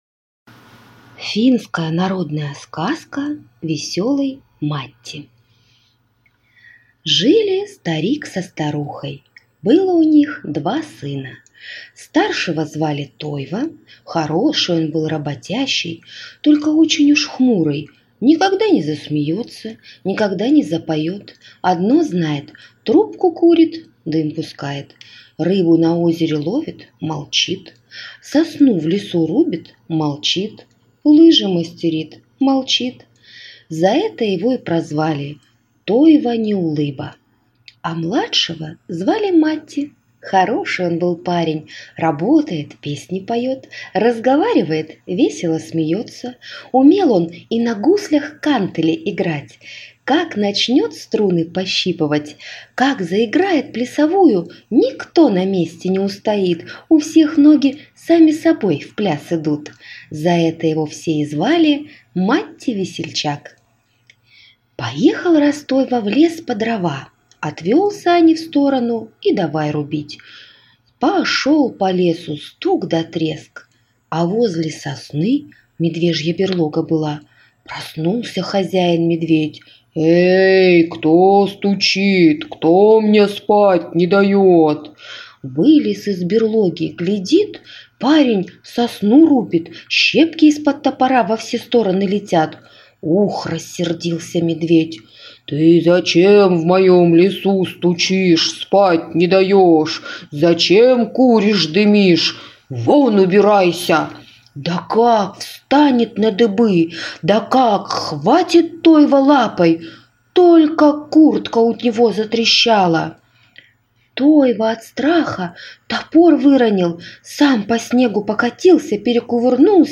Аудиосказка «Весёлый Матти»